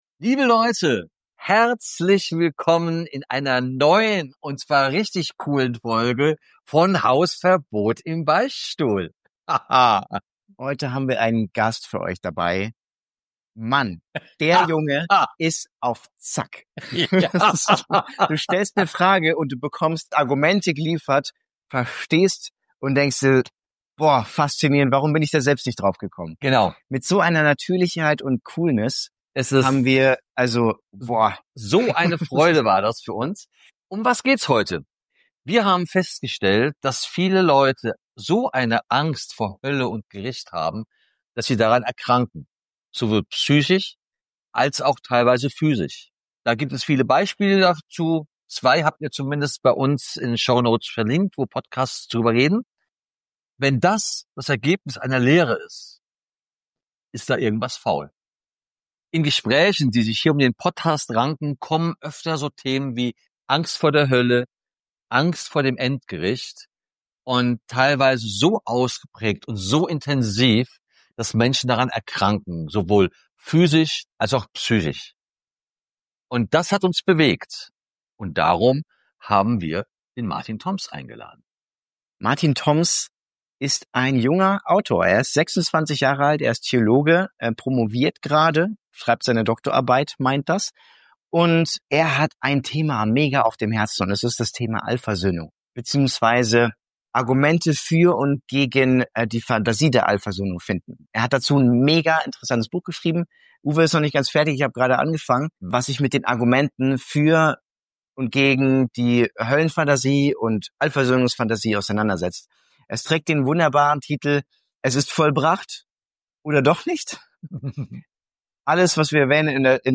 Spoiler: Es geht nicht um Strafe, sondern um Wiederherstellung. Ein Gespräch über Liebe, Freiheit und warum wir keine Angst mehr brauchen.